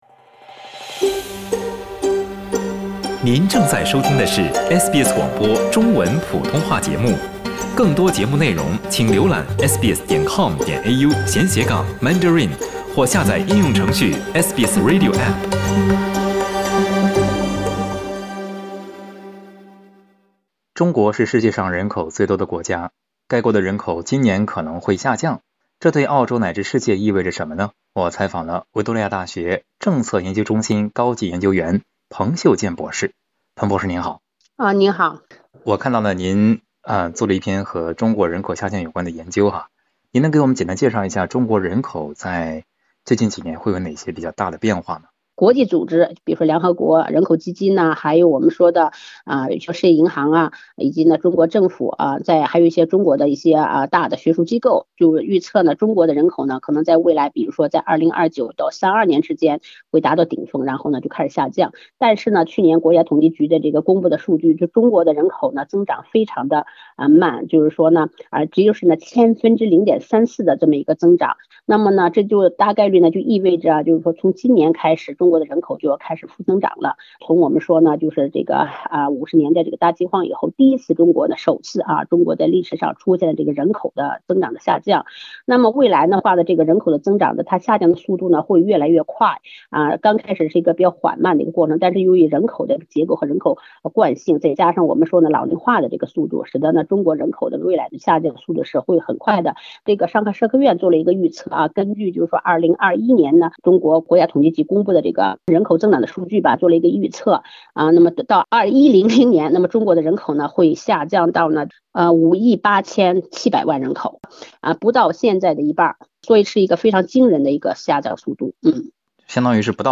在接受SBS普通话节目采访时